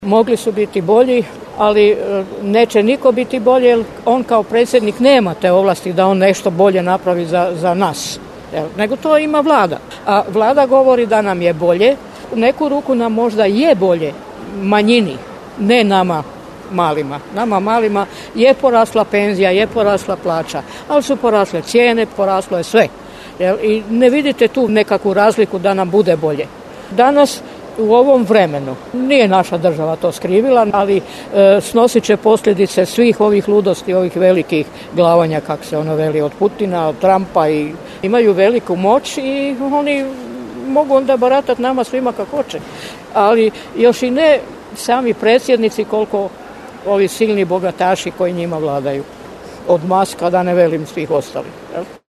Anketa
Gospođa prati situaciju iz šireg aspekta, tako da smo krenuli od nacionalne a zaustavili se na globalnoj političkoj sceni…